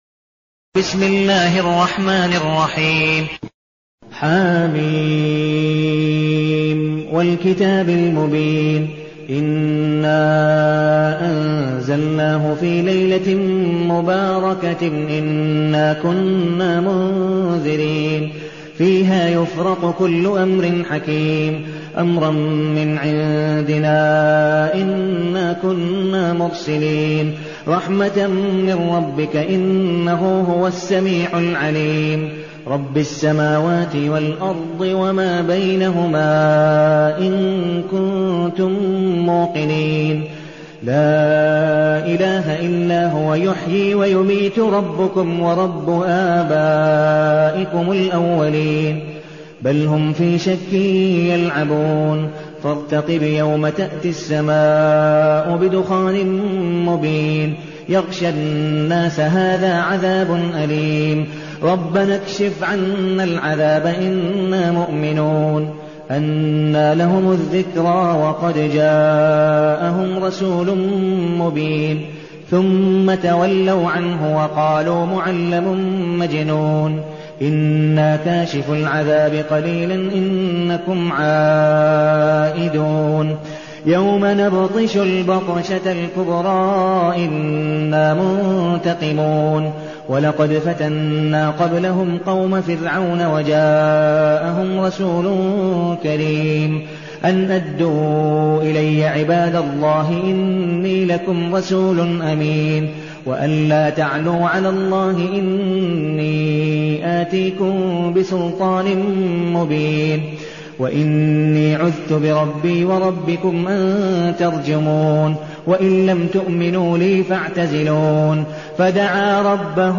المكان: المسجد النبوي الشيخ: عبدالودود بن مقبول حنيف عبدالودود بن مقبول حنيف الدخان